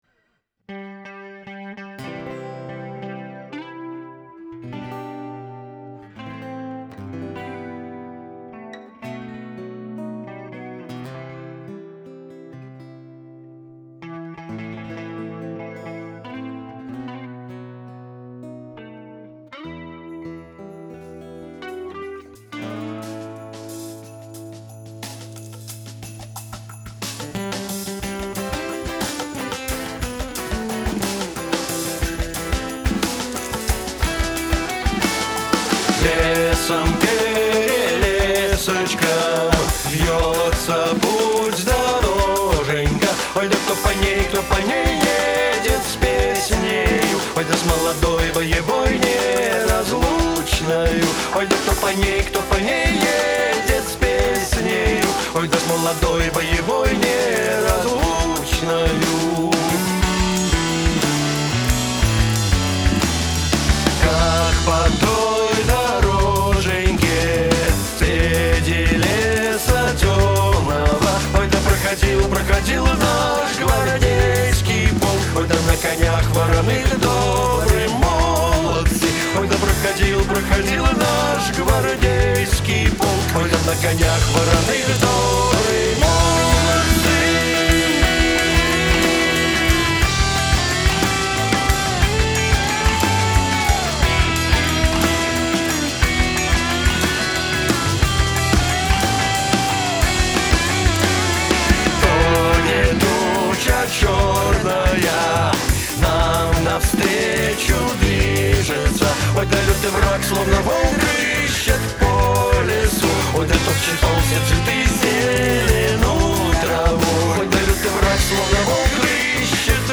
Сделали песню, и у нас тут спор возник, вокал в ролике слишком агрессивный или нет.
В общем подсушили по стерео-части, как теперь ощущения?